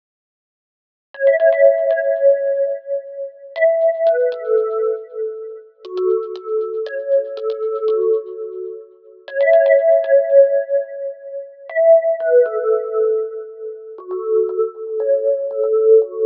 • Essential Pop Key Lead 2 118 bpm.wav
SC_Essentials_Key_Lead_2_118_bpm_JRF_EF1.wav